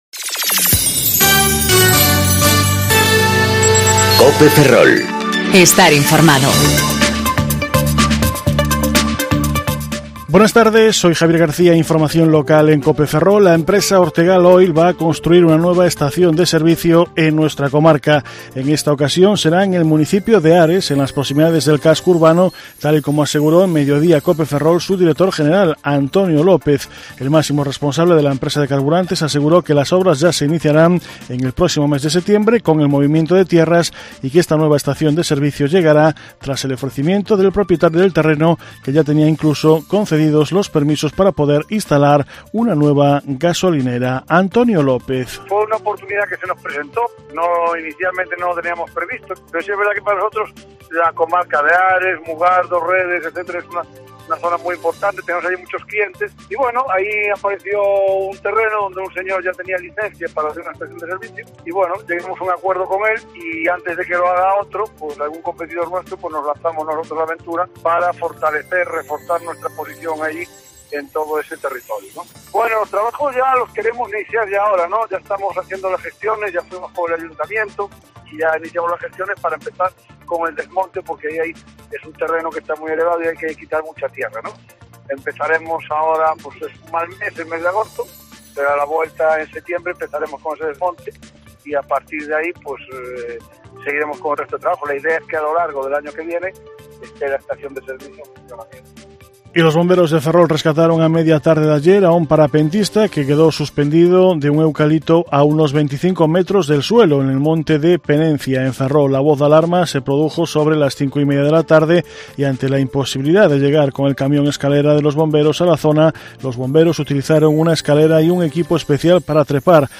Informativo Mediodía Cope Ferrol 19/08/2019 (De 14.20 a 14.30 horas)